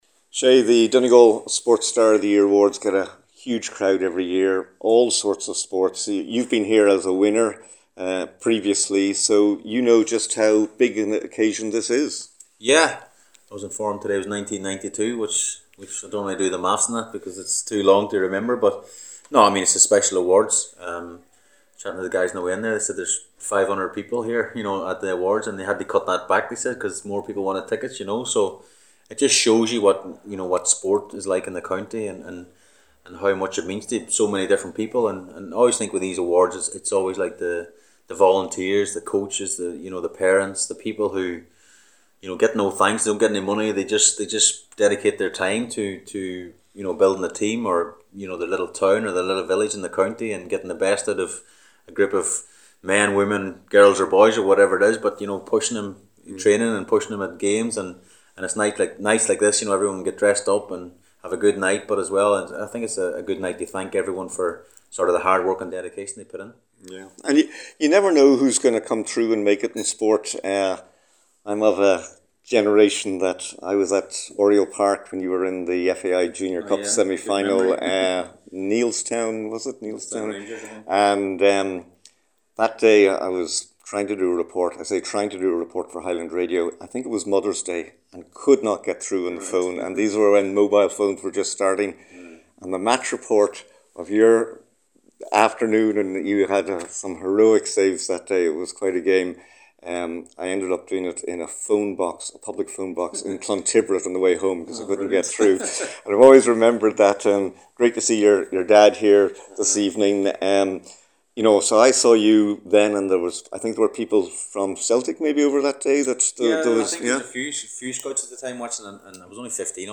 Shay Given was the special guest at the Donegal Sports Star Awards.